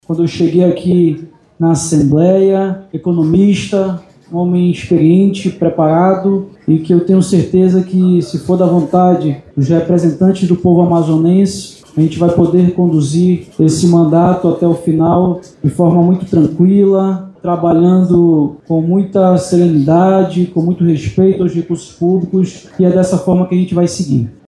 Em entrevista coletiva, Roberto Cidade disse estar preparado para conduzir o Estado.